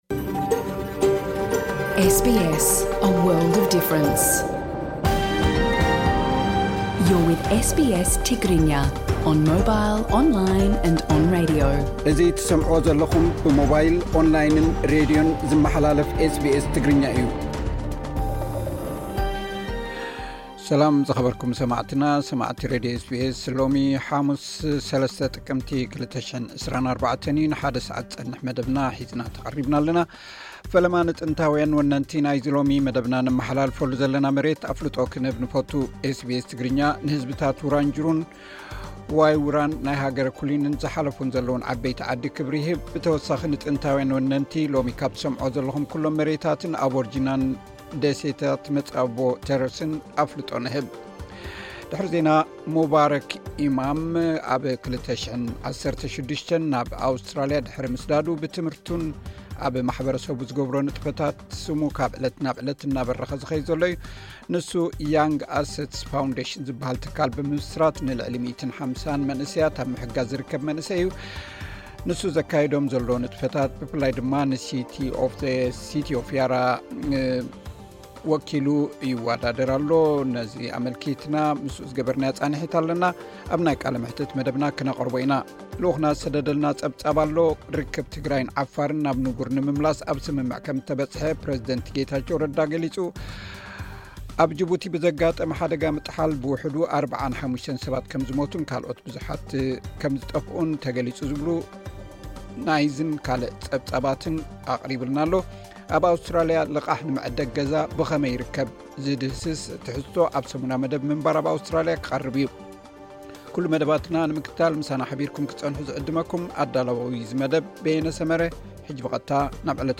ቀጥታ ፖድካስት ምሉእ ትሕዝቶ ኤስ ቢ ኤስ ትግርኛ (03 ጥቅምቲ 2024)